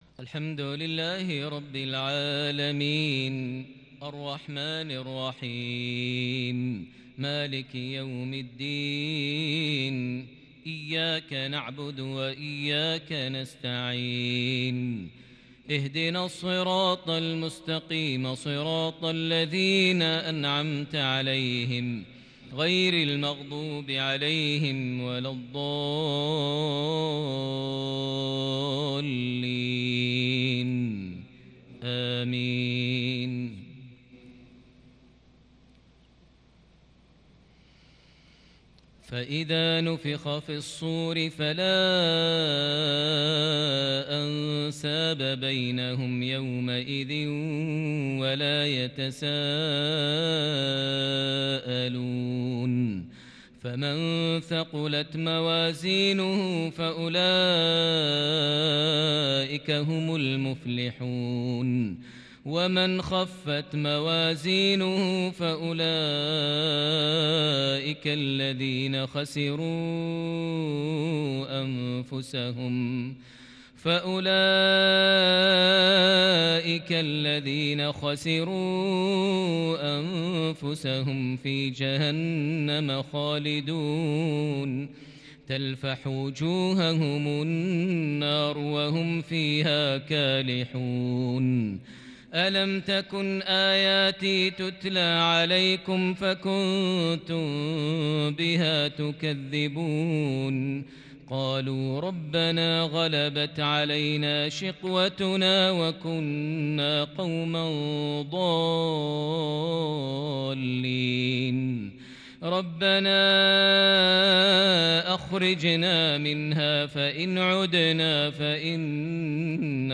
تأثر وخشوع | وأداء مبهر بالكرد للشيخ ماهر المعيقلي لخواتيم سورة المؤمنون | عشاء الأربعاء 7 محرم 1442هـ > 1442 هـ > الفروض - تلاوات ماهر المعيقلي